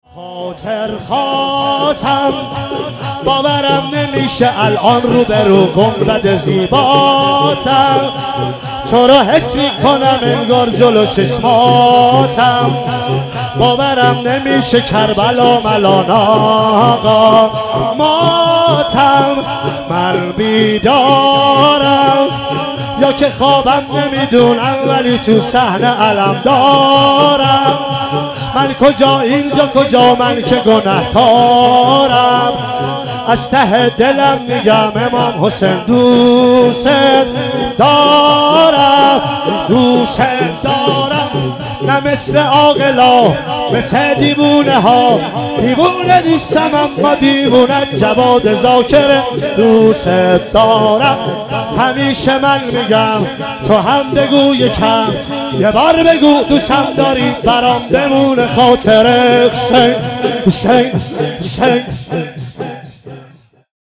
محرم 97